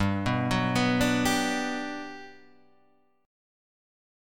G Major 7th